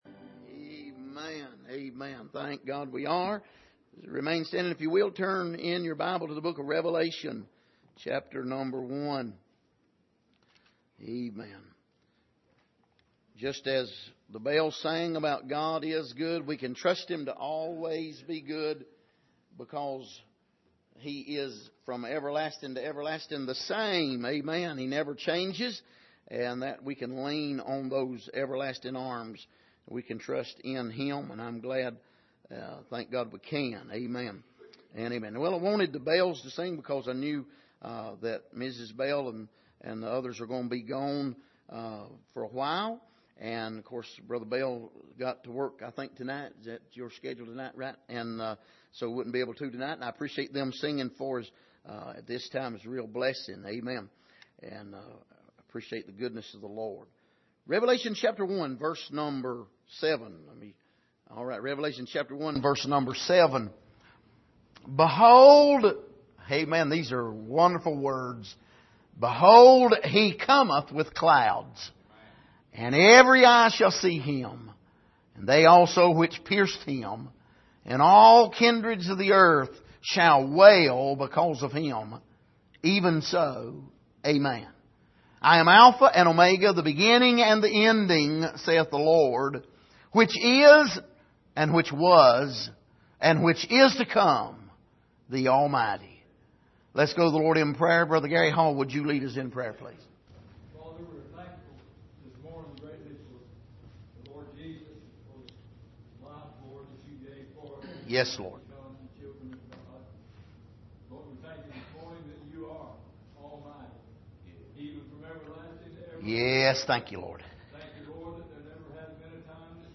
Passage: Revelation 1:7-8 Service: Sunday Morning